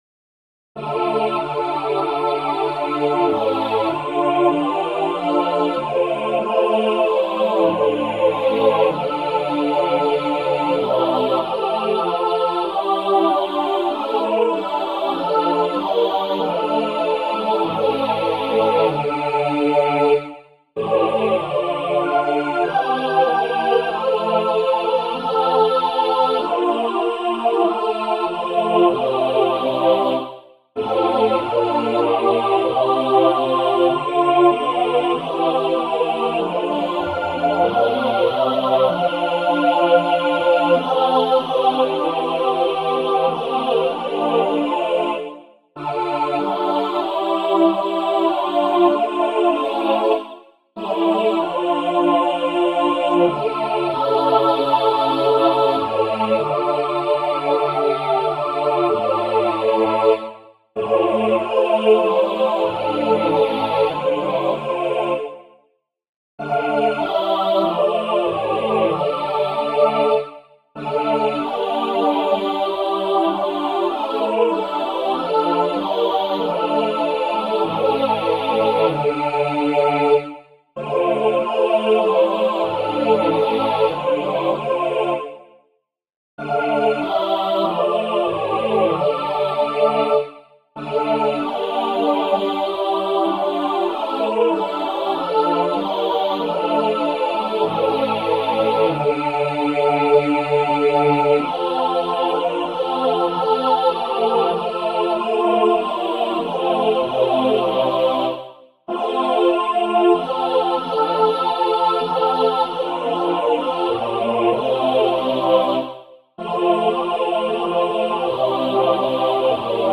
Key: d minor